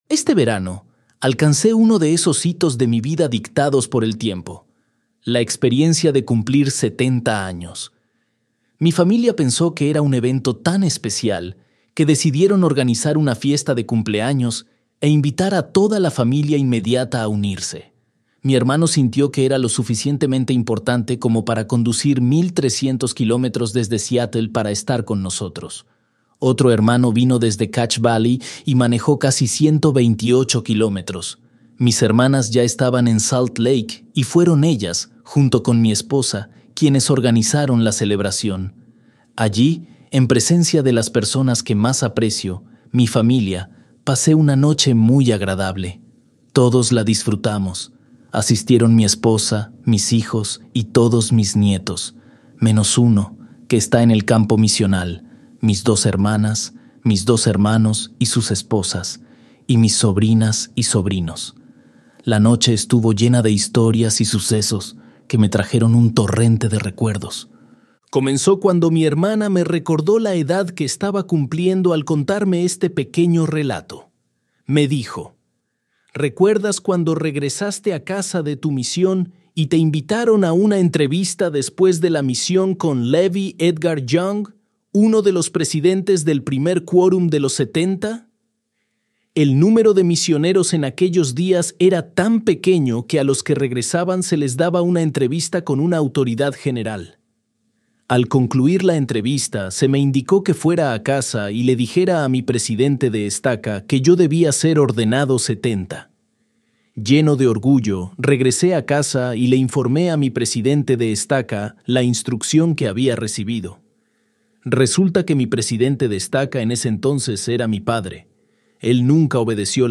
Miembro del Quórum de los Doce Apóstoles de La Iglesia de Jesucristo de los Santos de los Últimos Días